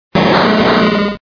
Fichier:Cri 0110 DP.ogg